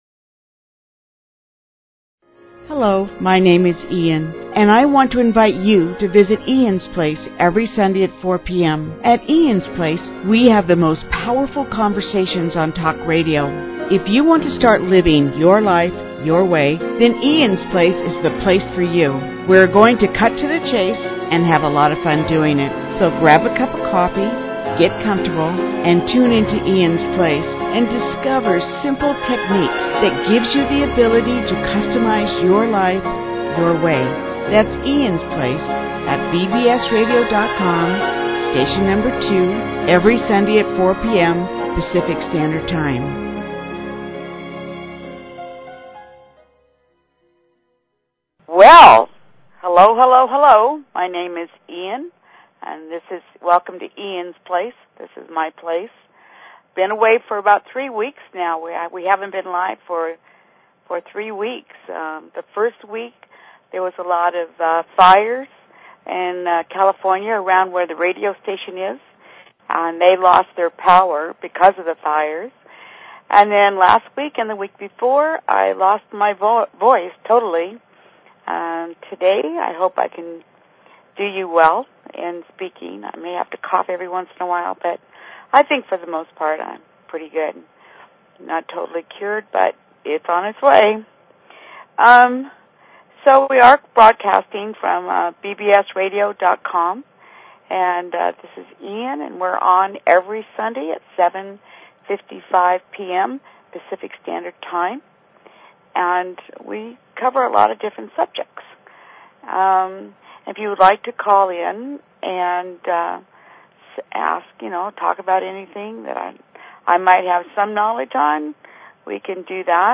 Talk Show Episode, Audio Podcast, Eans_Place and Courtesy of BBS Radio on , show guests , about , categorized as